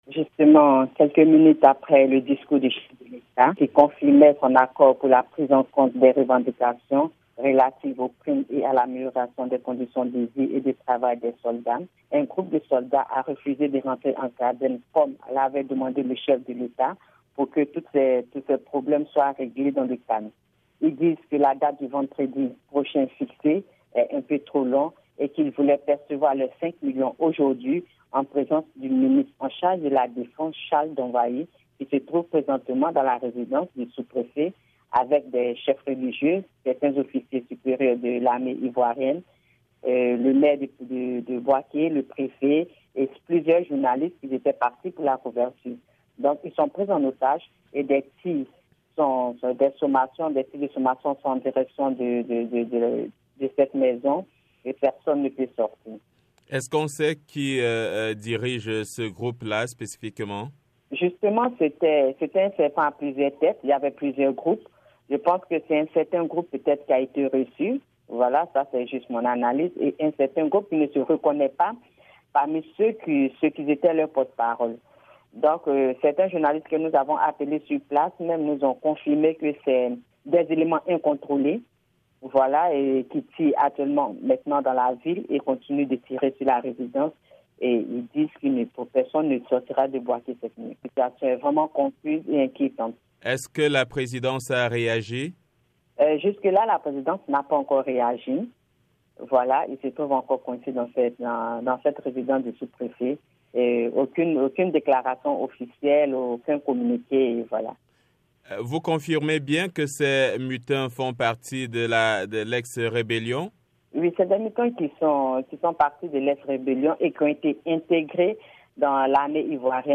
Récit